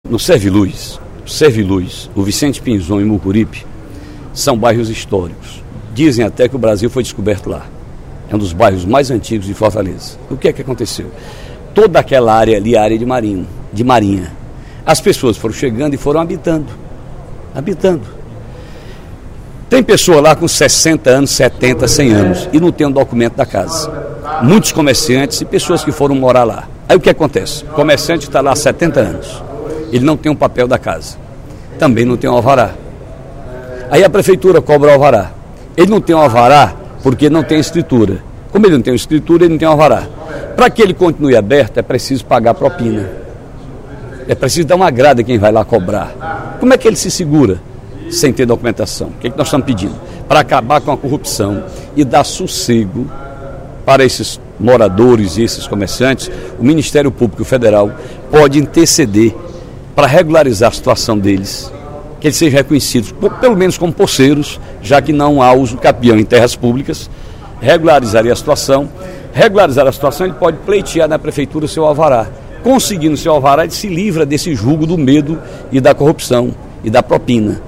Em pronunciamento durante o primeiro expediente da sessão plenária desta quinta-feira (21/02), o deputado Ferreira Aragão (PDT) fez um apelo ao Ministério Público Federal e à Prefeitura de Fortaleza que intercedam pela regularização da situação dos moradores dos bairros Serviluz, Mucuripe e Vicente Pinzón.